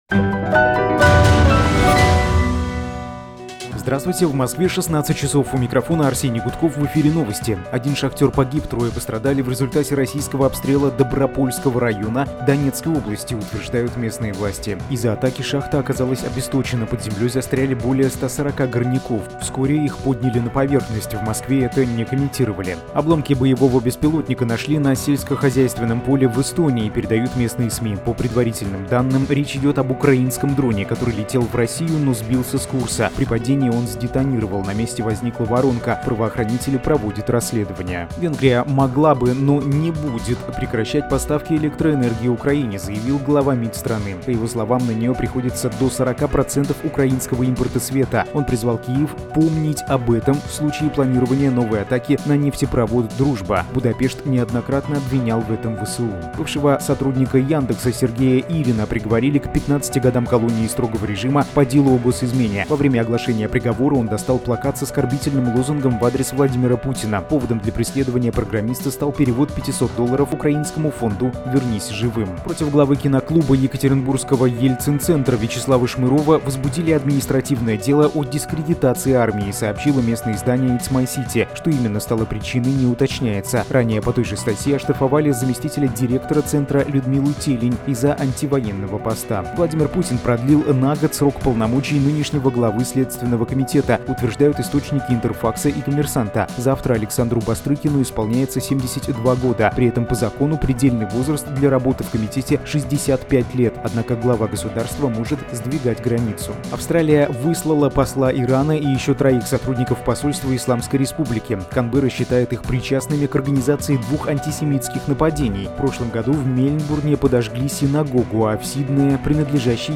Слушайте свежий выпуск новостей «Эха»